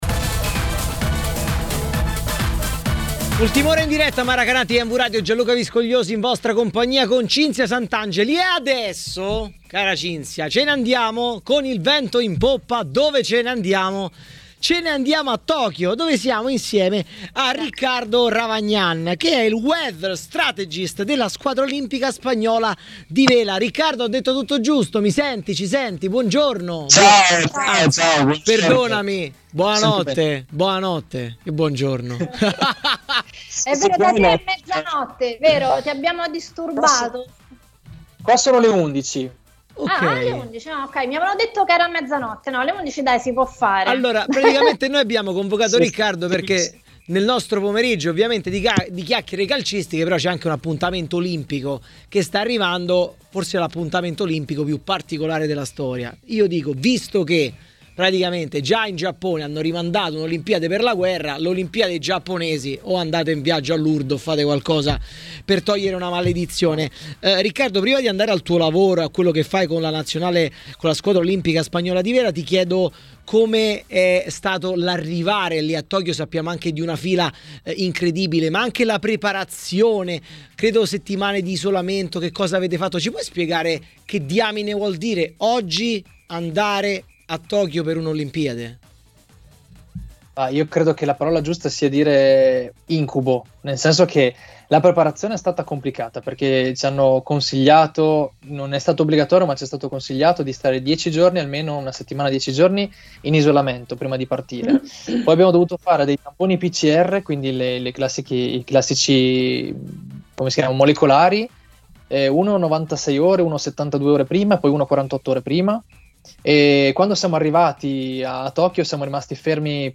A intervenire in diretta a Maracanà, nel pomeriggio di TMW Radio